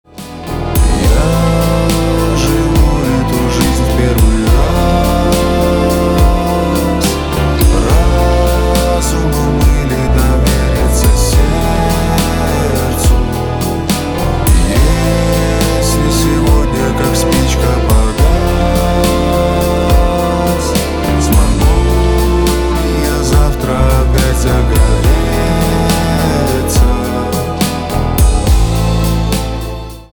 поп
чувственные , грустные
барабаны , скрипка , гитара